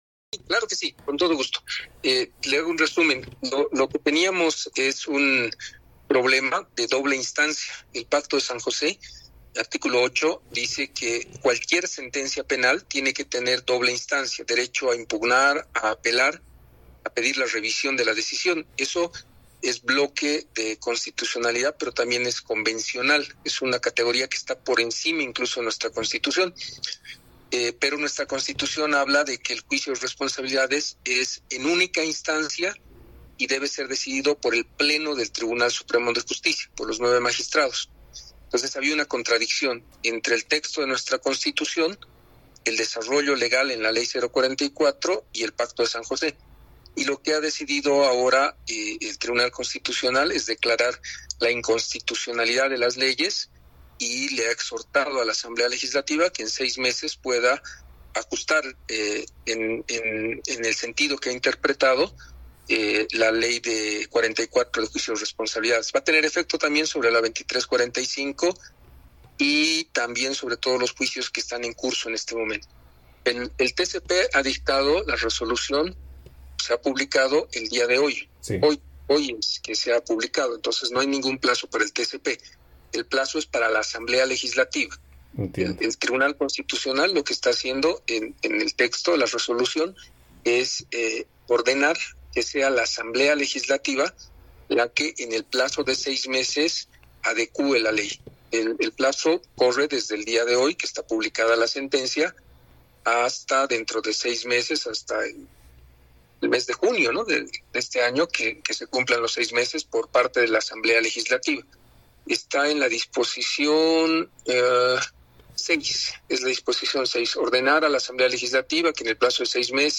audio-exministro-Lima.mp3